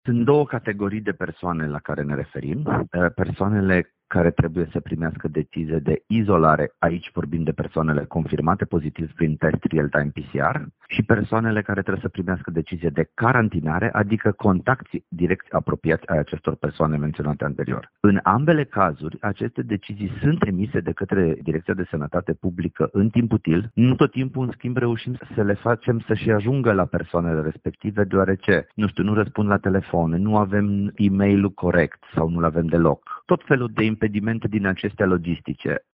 Șeful DSP Timiș a mai declarat că persoanele care trebuie să stea în izolare fără o decizie din partea instituției nu ar trebui să își facă probleme pentru obținerea concediului medical: